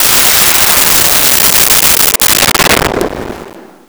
Missle 03
Missle 03.wav